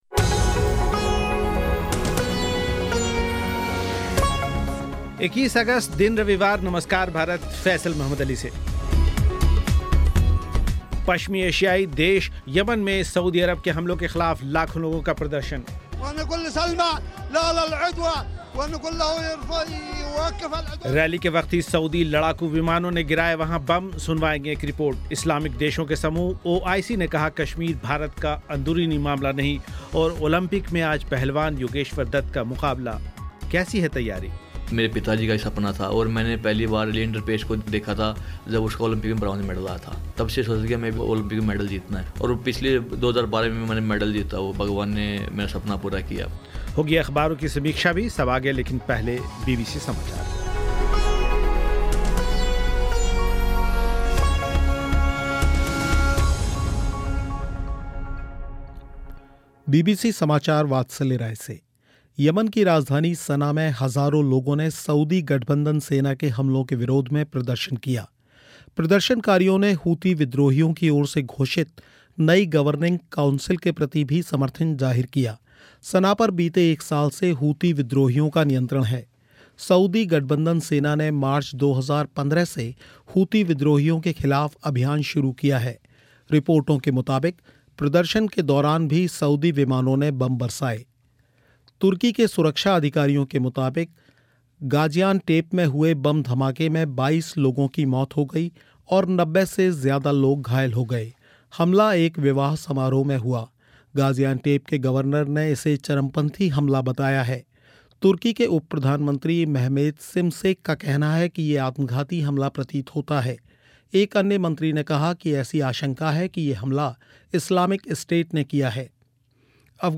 पश्चिमी एशियाई देश यमन में सऊदी अरब के हमलों के ख़िलाफ़ लाखों लोगों का प्रदर्शन, रैली के वक्त ही सऊदी लड़ाकू विमानों ने गिराए वहां बम, सुनवाएंगे एक रिपोर्ट